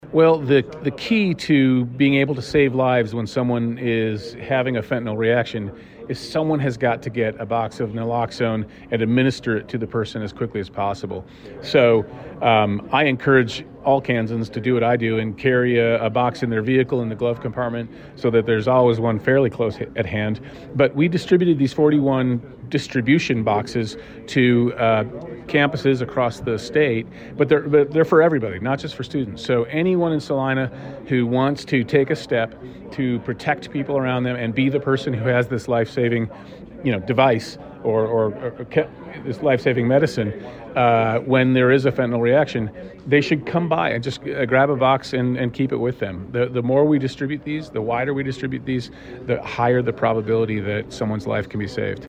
The Attorney General told KSAL News the kits can quickly reverse the effects of a Fentanyl overdose, and save a life.